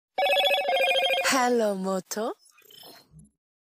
PLAY hello moto sound effect